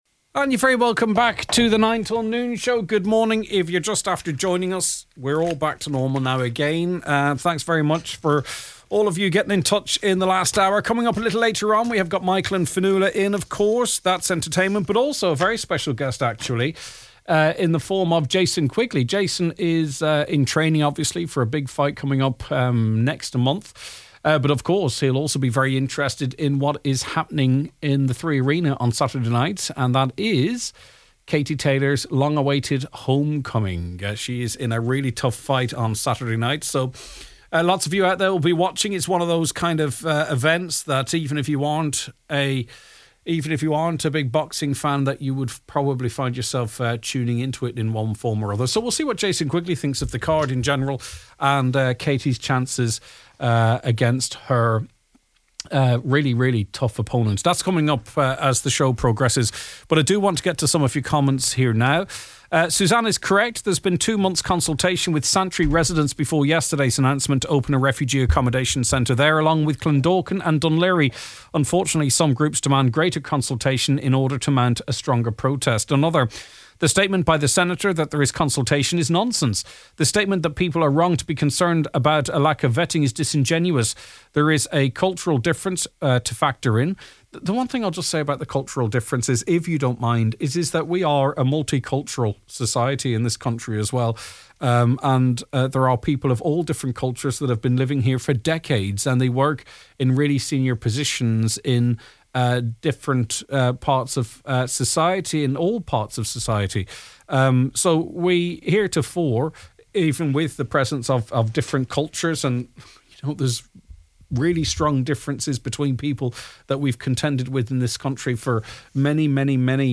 The Nine Til Noon is broadcast live each weekday between 9am to 12noon.